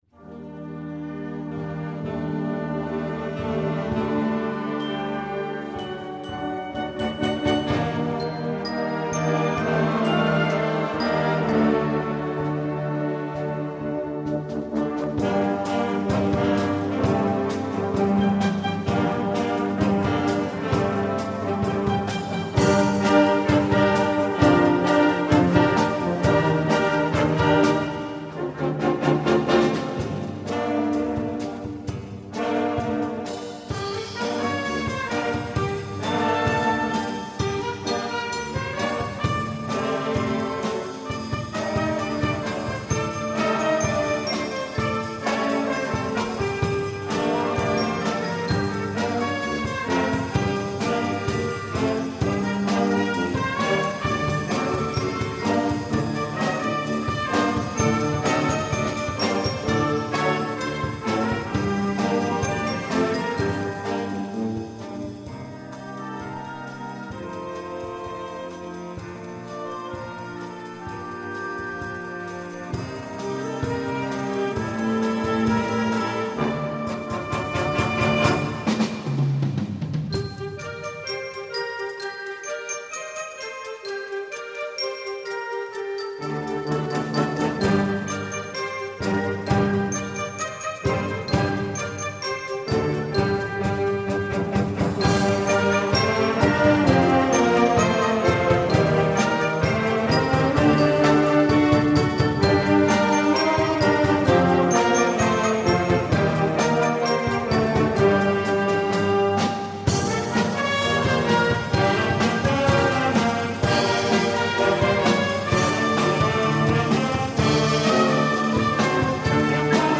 Gattung: Konzertwerk für Jugendblasorchester
Besetzung: Blasorchester